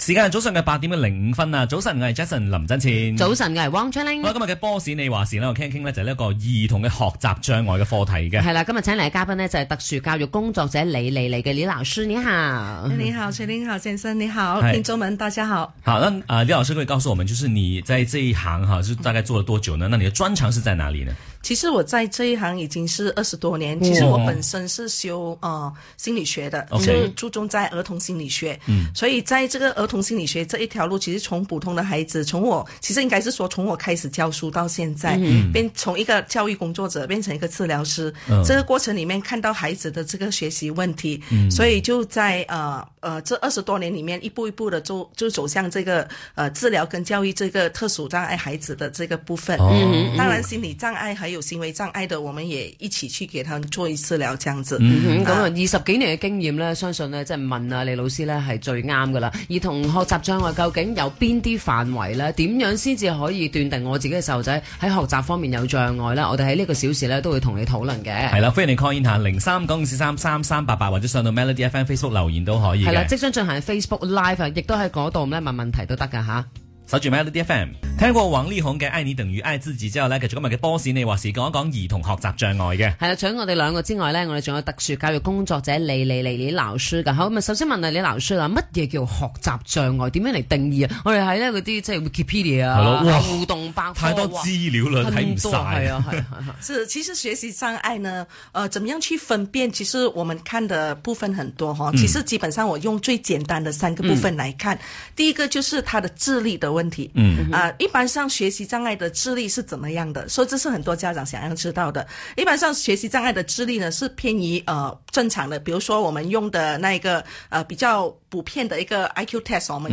Media Interview